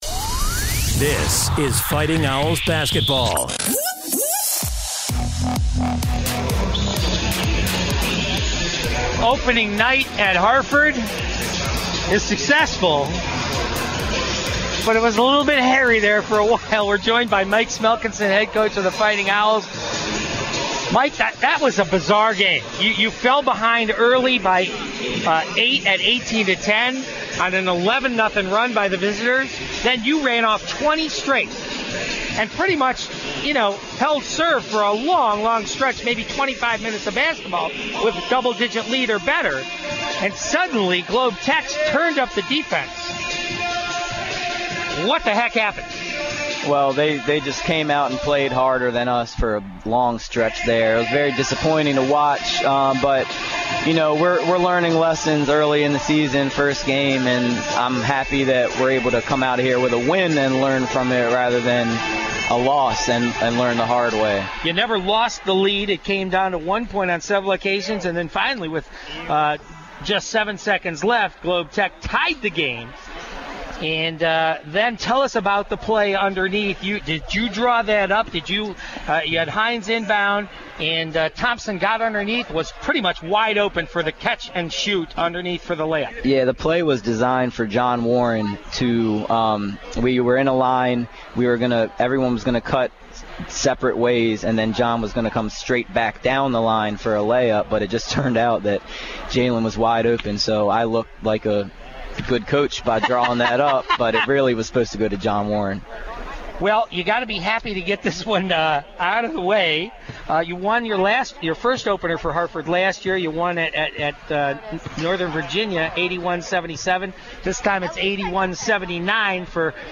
11/3/15: Harford Men's Basketball Post Game Show